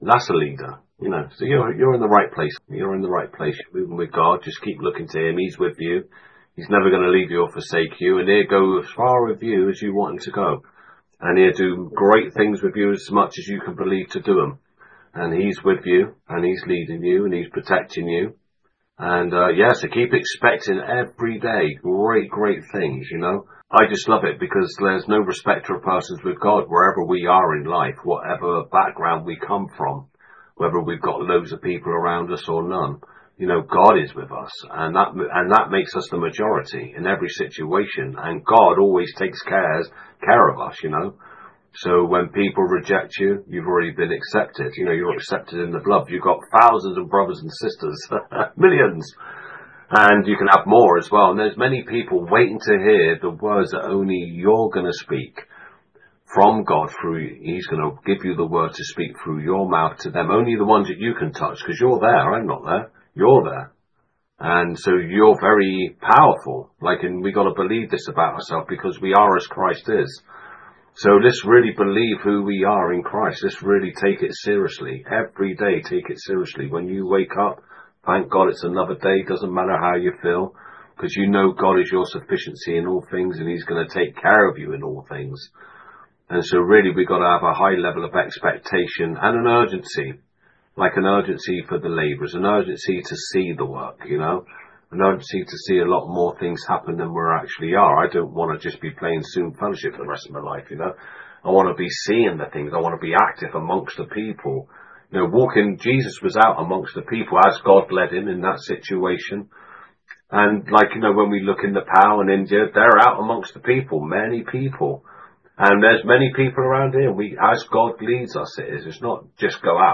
From May Monthly Leaders Meeting